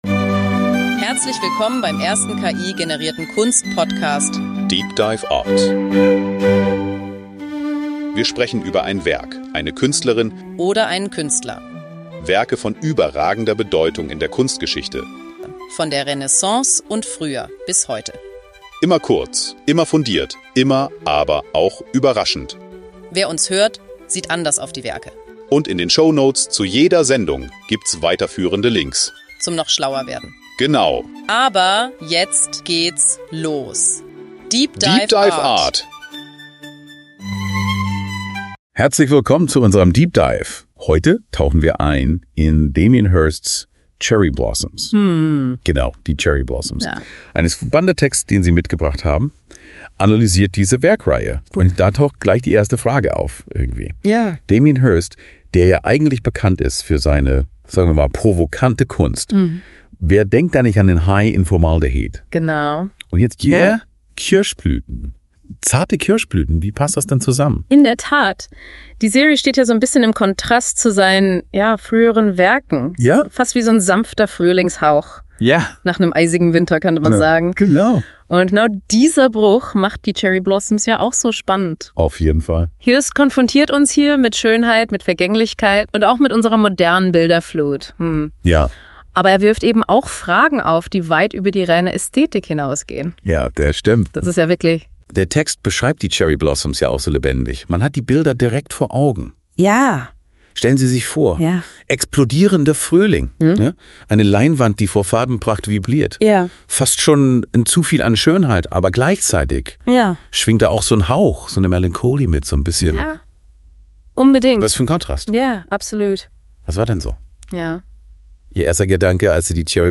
Die Gesprächspartner erörtern die Ambivalenz der Werke und die provokante Natur von Hirsts Kunst, die zum Nachdenken anregt und verschiedene Reaktionen hervorruft. DEEP DIVE ART ist der erste voll-ki-generierte Kunst-Podcast.
Die beiden Hosts, die Musik, das Episodenfoto, alles.